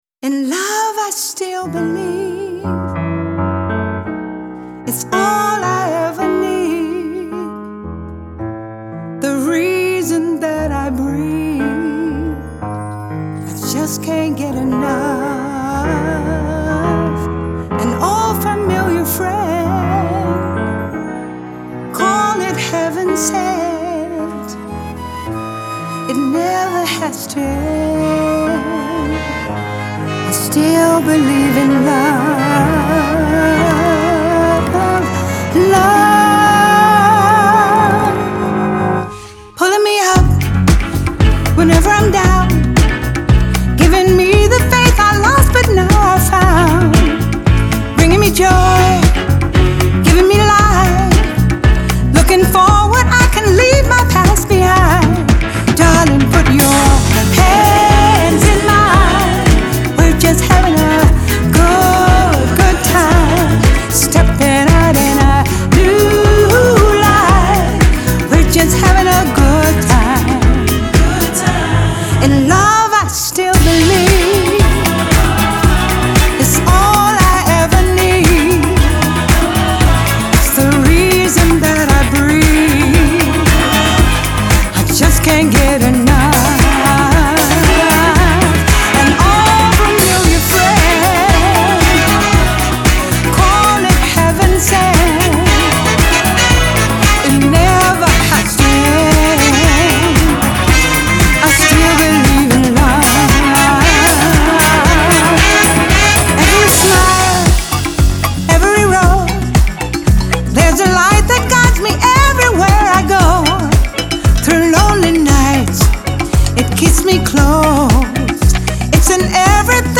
Genre : Soul